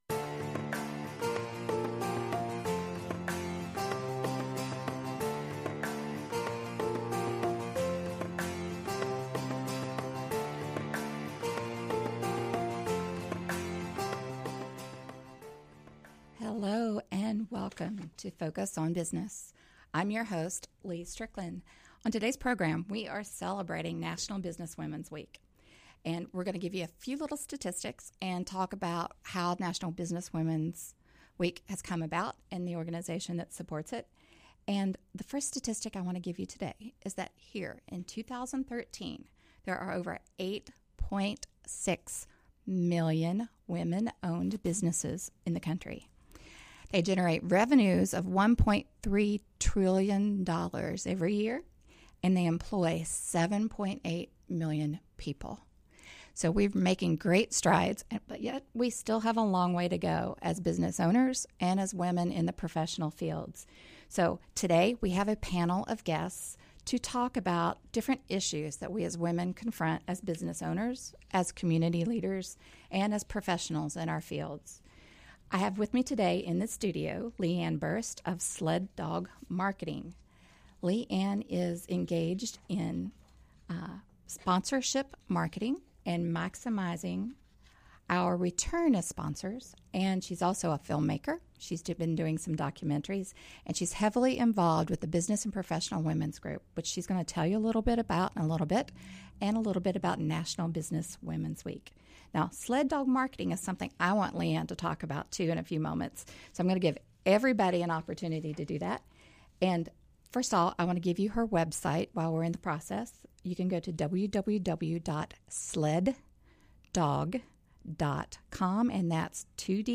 Women Business Owners and Professionals talk career, opportunities and challenges facing women in the workplace and marketplace.